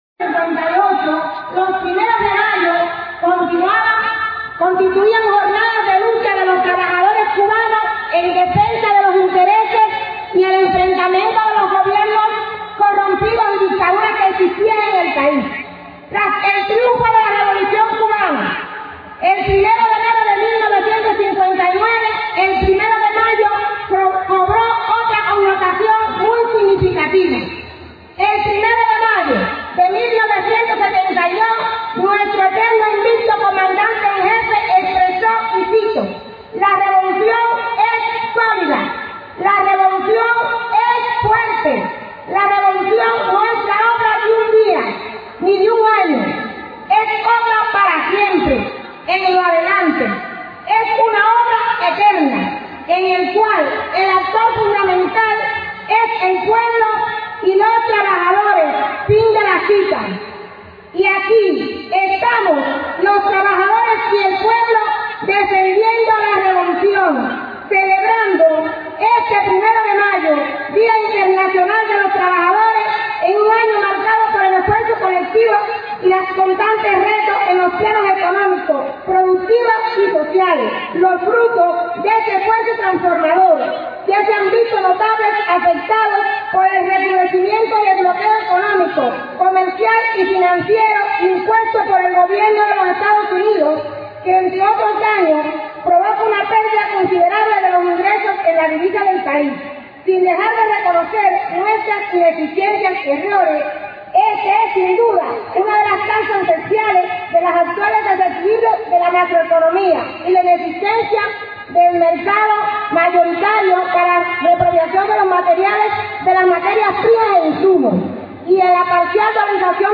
Trabajadores granmenses y sus familiares desfilaron, hoy, para reafirmar su apoyo a la obra revolucionaria, a sus dirigentes y a la voluntad socialista de crear juntos por Cuba.